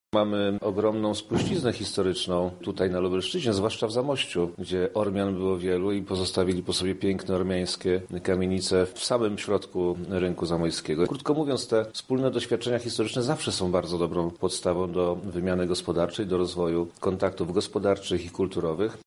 O tym, co łączy Polskę i Armenię, mówi wojewoda lubelski Przemysław Czarnek: